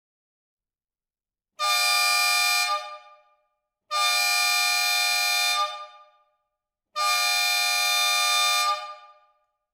Deze luchthoorn werkt op 12V en is uitgevoerd met drie tonen: hoog, midden en laag. Het model levert een geluidsniveau van 117dB en heeft een totaal vermogen van 240W.